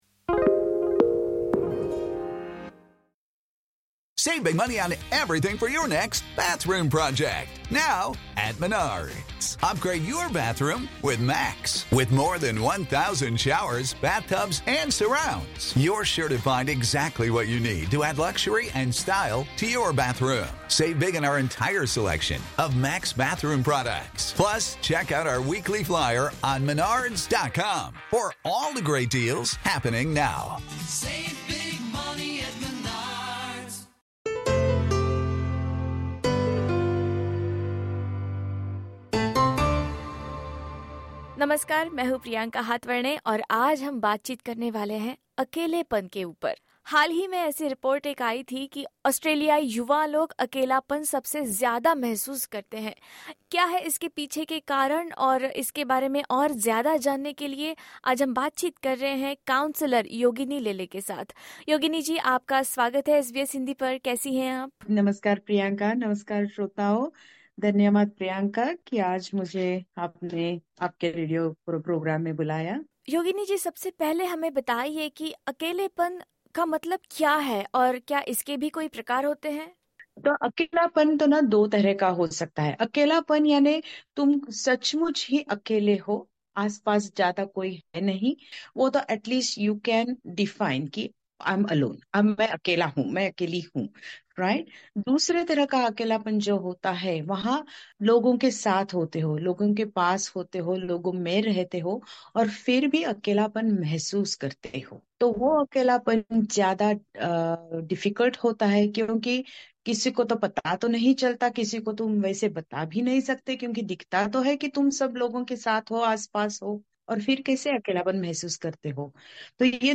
SBS Hindi conducted an interview with a professional counsellor…